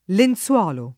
lenZU0lo] s. m. — pl. i lenzuoli, considerati uno per uno oppure in senso fig. (es.: tre lenzuoli ancora da rattoppare; certi avvisi grandi come lenzuoli; beduini avvolti in strani lenzuoli); ma in senso collettivo le lenzuola, il paio che si stende sul letto, o anche un numero non definito (es.: le lenzuola di bucato; rimboccare le lenzuola; cacciarsi sotto le lenzuola; consumar le lenzuola; tutte le lenzuola dell’albergo) — pop. tosc. lenzolo [lenZ0lo]: coperte e lenzoli distesi [kop$rte e llenZ0li diSt%Si] (Manzoni); tra i massi che da lontano paiono lenzòli stesi [